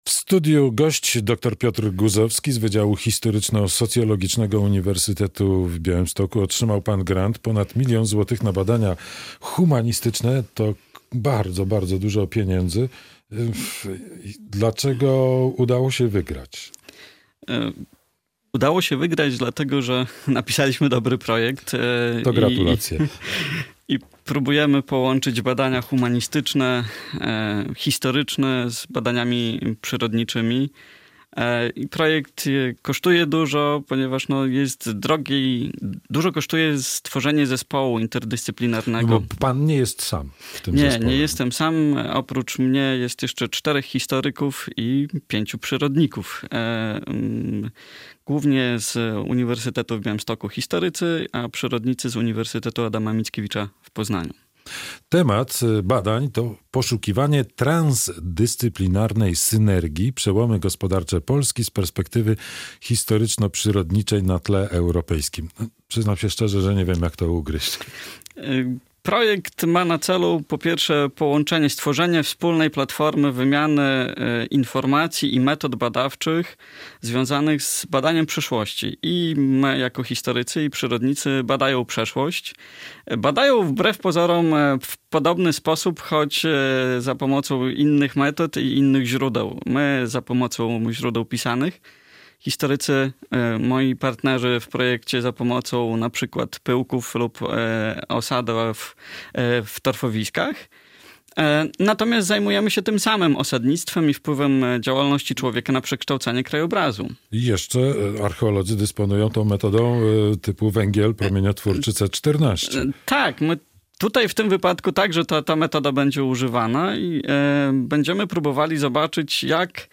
historyk